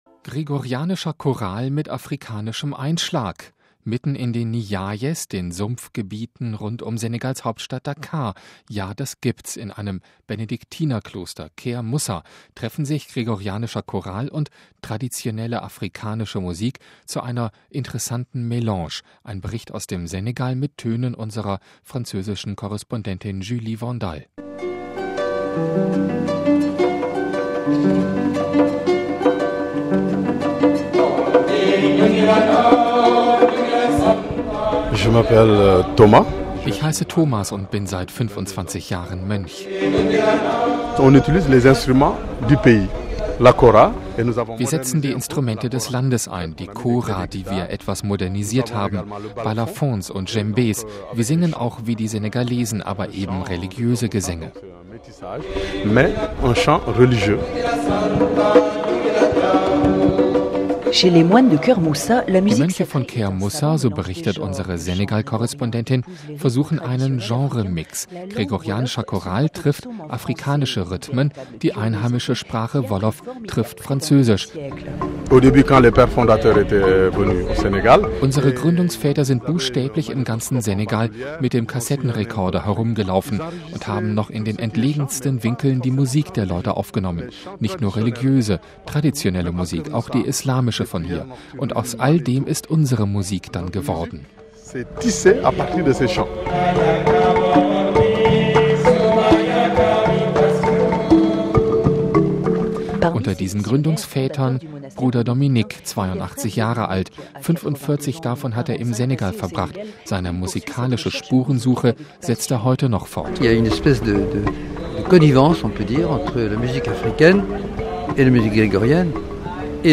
In einem Benediktinerkloster namens Keur Moussa treffen sich gregorianischer Choral und traditionelle afrikanische Musik zu einer bewegenden Melange. Ein Bericht aus dem Senegal